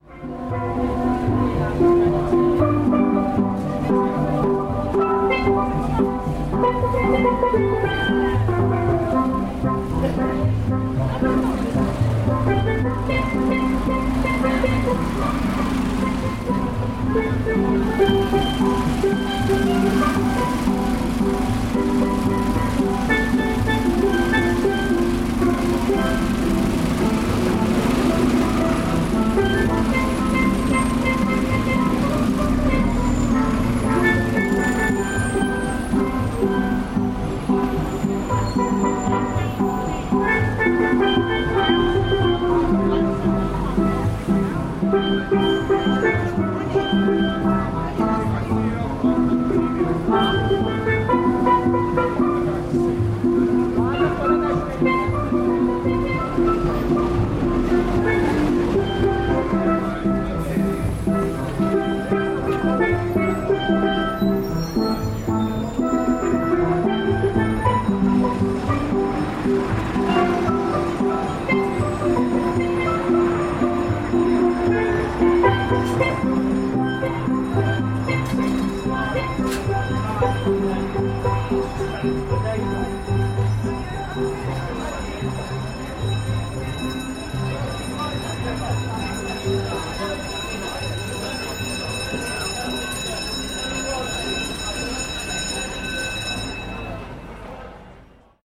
Steel drums at Brixton tube station
Field recording from the London Underground by Cities and Memory.